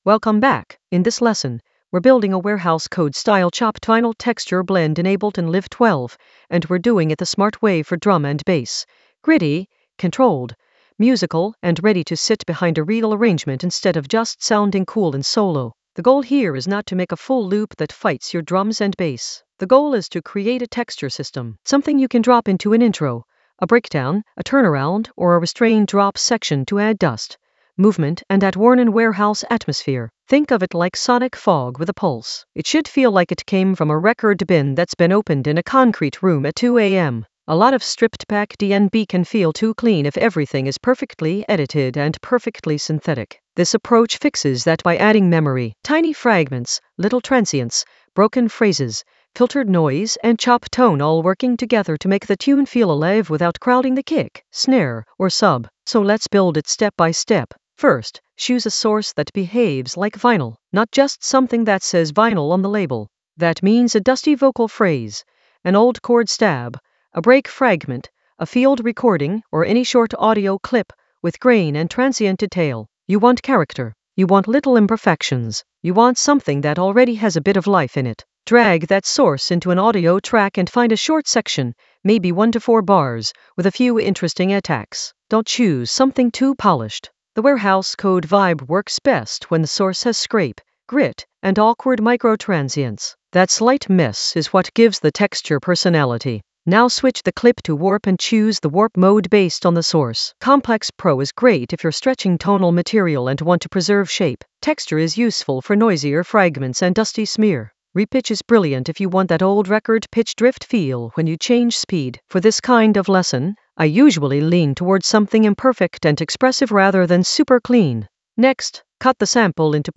Narrated lesson audio
The voice track includes the tutorial plus extra teacher commentary.
An AI-generated intermediate Ableton lesson focused on Warehouse Code approach: a chopped-vinyl texture blend in Ableton Live 12 in the FX area of drum and bass production.